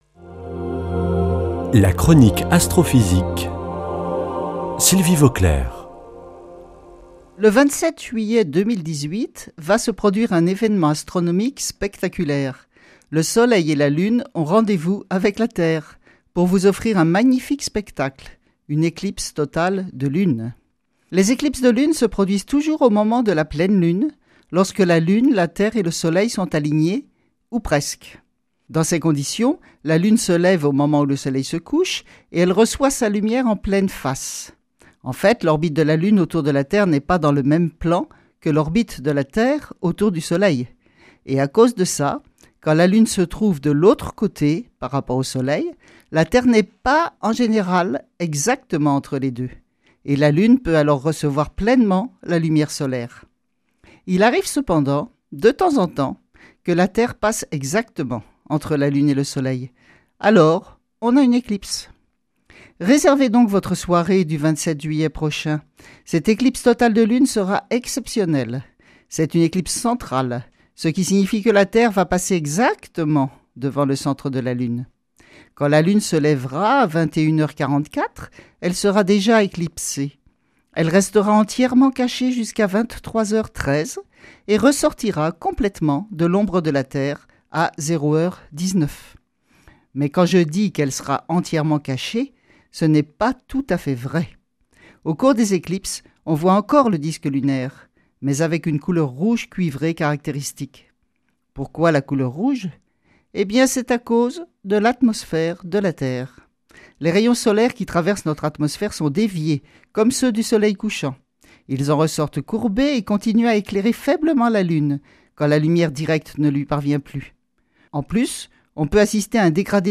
Chronique Astrophysique du 02 juil.
Astrophysicienne